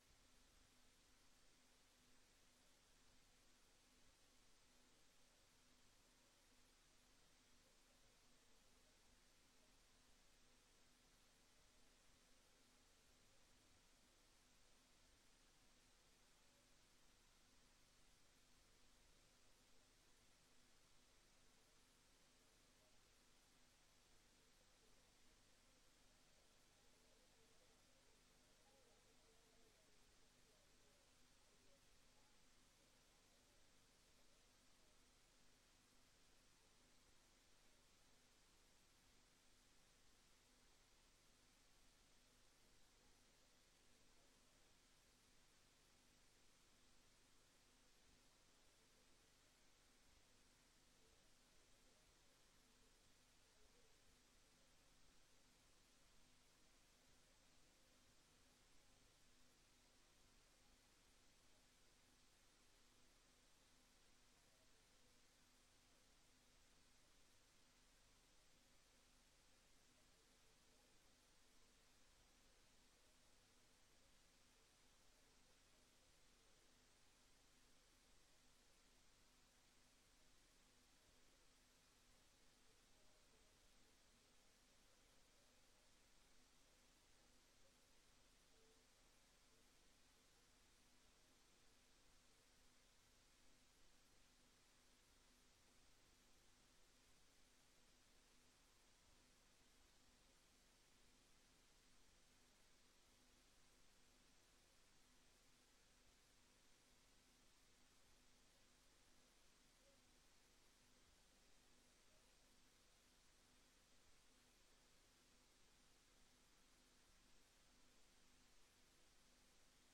Raadsvergadering Papendrecht 16 december 2024 20:00:00, Gemeente Papendrecht
Locatie: Raadzaal